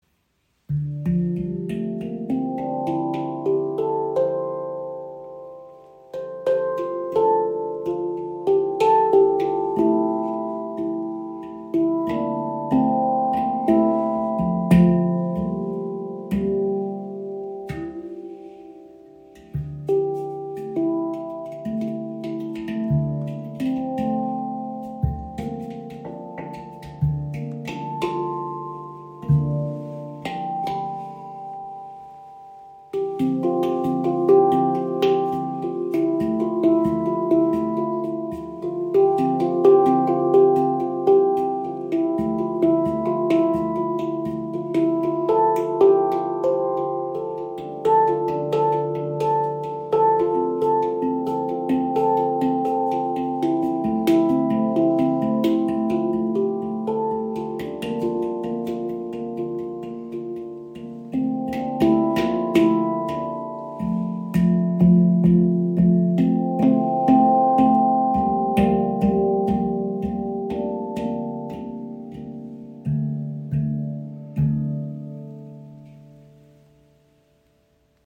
Handpan ShaktiPan | D Kurd | 12 Klangfelder – lebendig & warm
• Icon D Kurd – emotionaler Charakter D – (F G) A Bb C D E F G A C
Gefertigt aus Ember Steel, überzeugt die ShaktiPan durch eine warme, lange tragende Schwingung und einen besonders weichen, sensiblen Anschlag.
Die D-Kurd-Stimmung verbindet einen mollartigen Charakter mit einer geheimnisvollen, leicht orientalischen Klangfarbe.
Die D Kurd Stimmung wirkt erdig, tiefgründig und beruhigend – perfekt für meditative Klänge und harmonische Improvisationen.